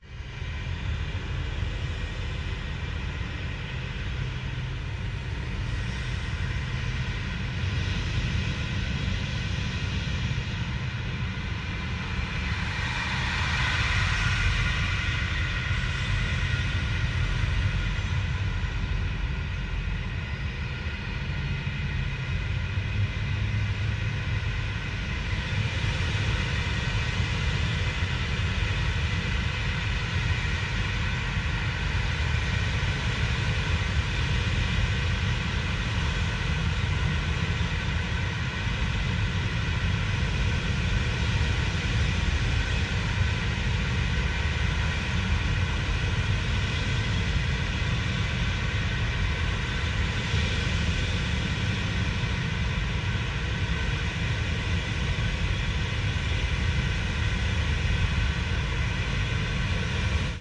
声景。外层空间" archi soundscape space2
描述：Surge（合成器）、Rayspace（混响）和Dronebox（共振延迟）的实例
标签： 氛围 ambiant 氛围 环境 气氛 无人驾驶飞机 邪恶 恐怖 外空 吓人 声景观 空间
声道立体声